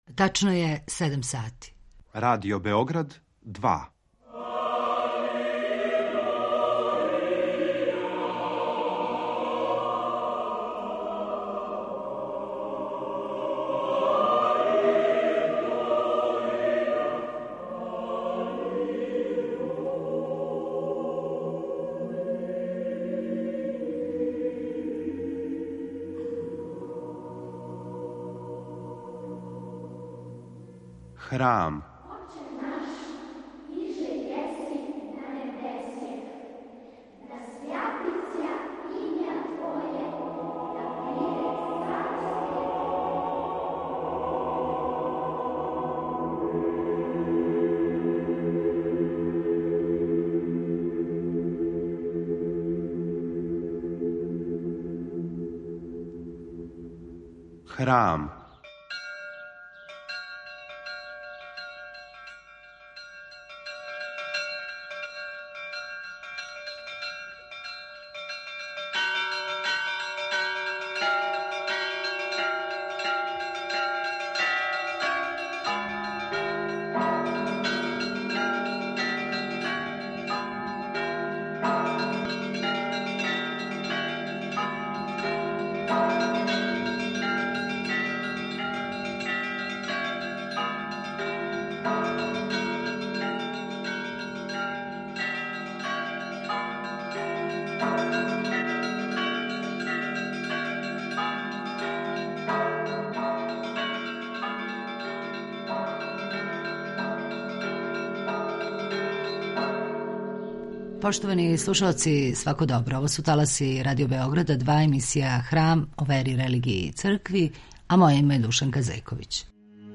Беседи Његова Светост Патријарх српски г. др Порфирије